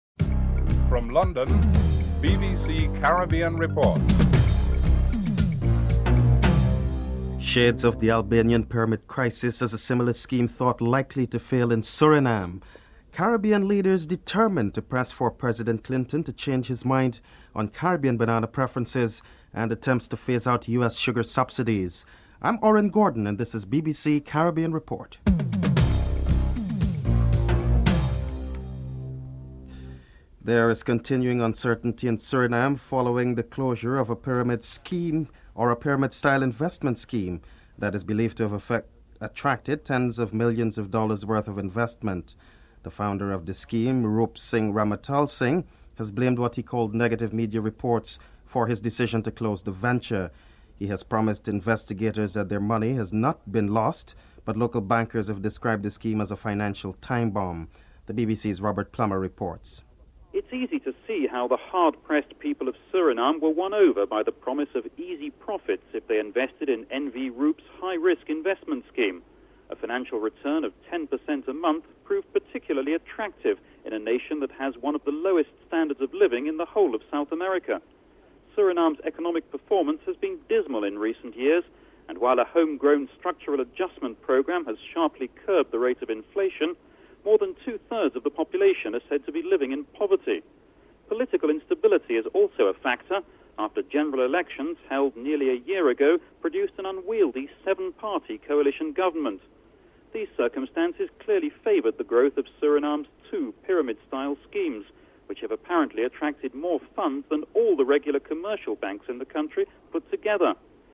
1. Headlines (00:00-00:27)
Prime Minister of St Lucia, Vaughn Lewis, Prime Minister of Grenada, Keith Mitchell, Foreign Minister of Trinidad and Tobago, Ralph Maraj, Labour Party Member of the European Union, Glenys Kinnock, Member of the European Union, Terry Wynn are interviewed.